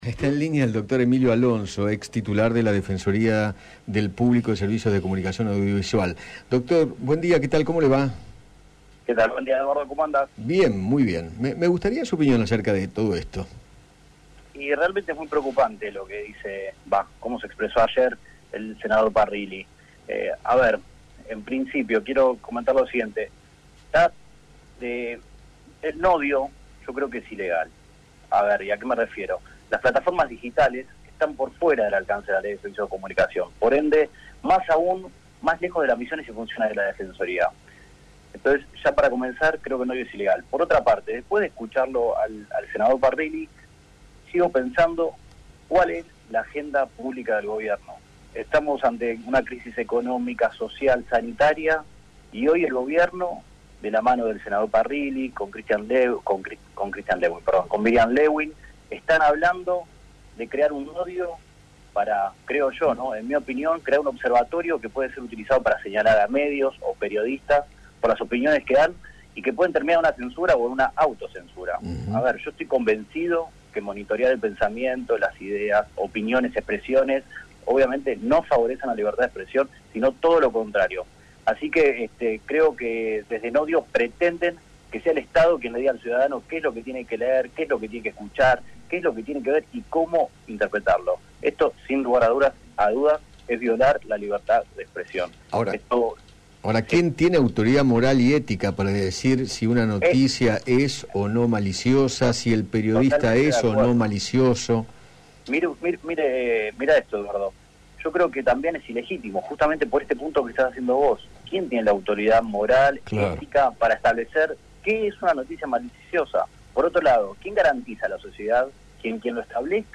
Emilio Alonso, ex Encargado de la Defensoría del Público, dialogó con Eduardo Feinmann sobre el organismo creado para supervisar las noticias que divulgan los medios de comunicación y sostuvo que “decirle al ciudadano qué tiene que ver o escuchar atenta contra la libertad de expresión”.